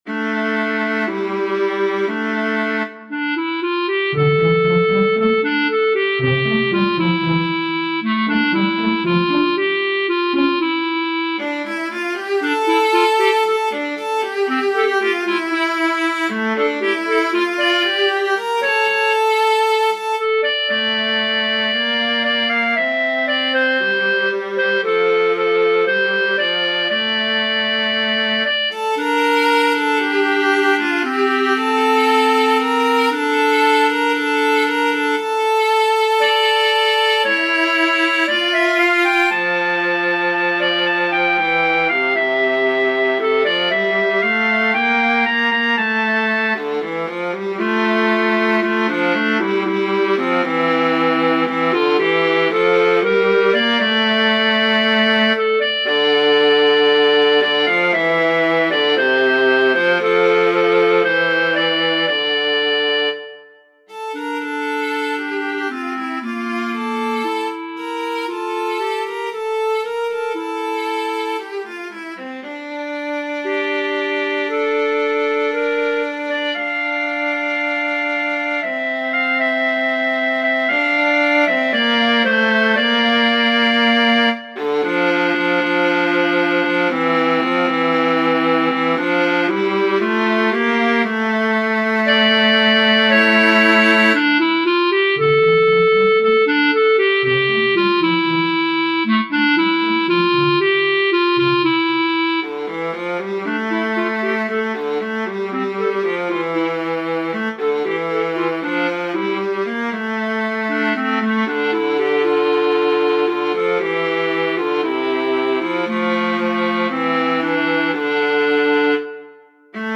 Clarinet, Viola